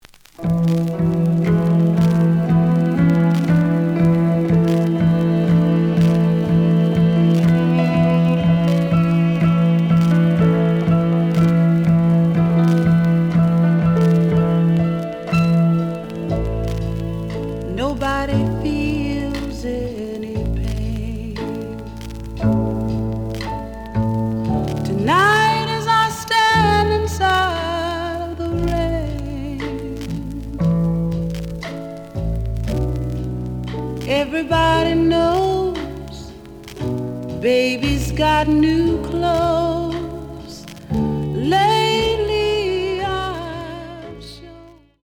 The audio sample is recorded from the actual item.
●Genre: Soul, 70's Soul
Some periodic noise on B side.)